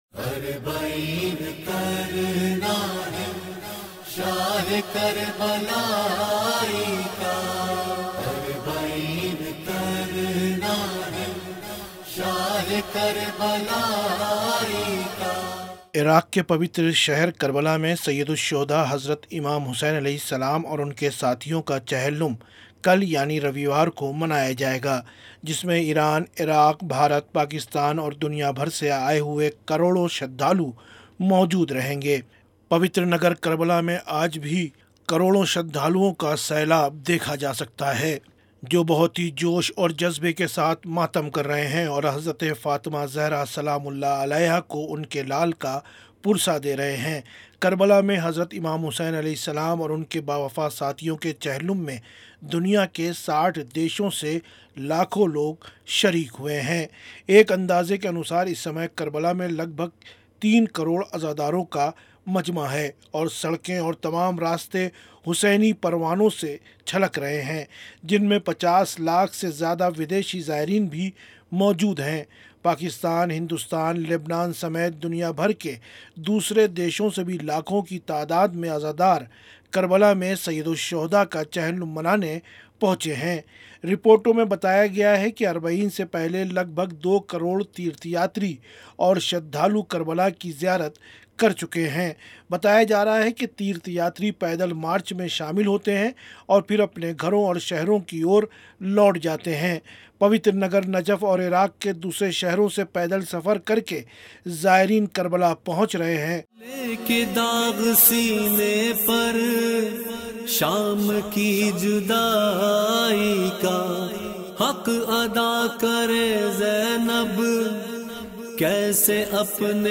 लब्बैक या हुसैन की सदाओं से गुंज रही है करबला...